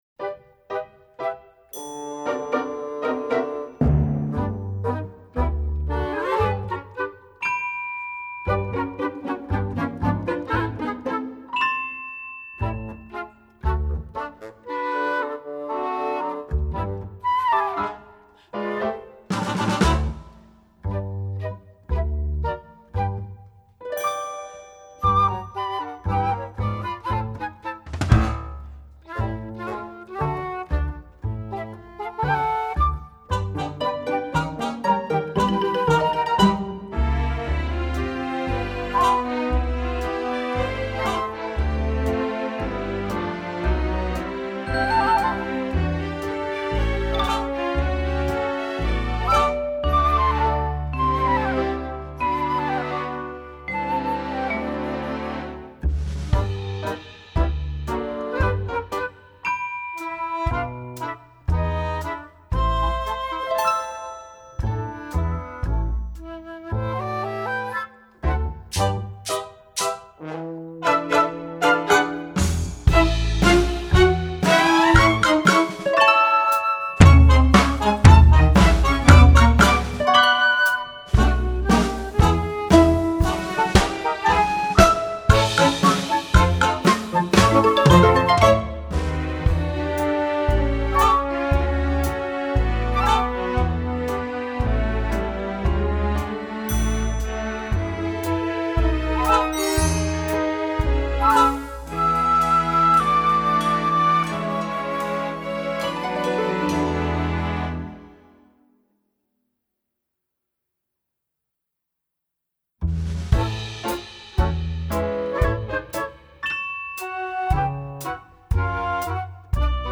Minus Track